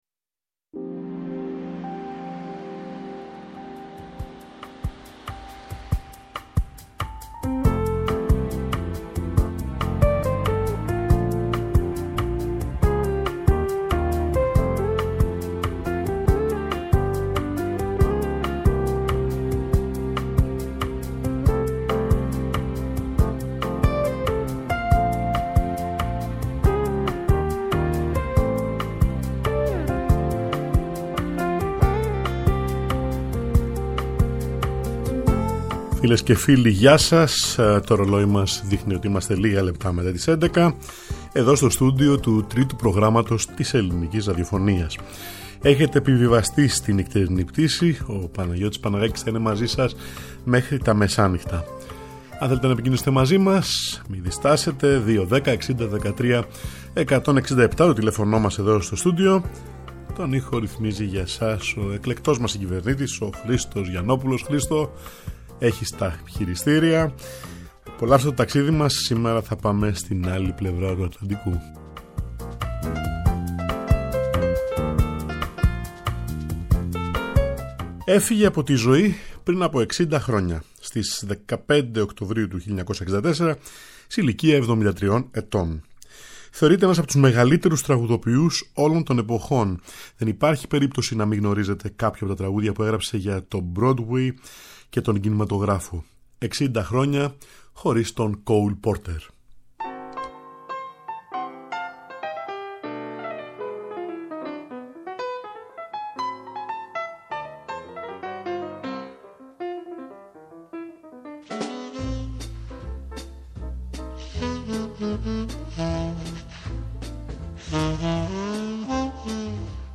Διάσημοι μουσικοί της jazz διασκευάζουν γνωστές συνθέσεις ενός από τους μεγαλύτερους τραγουδοποιούς όλων των εποχών.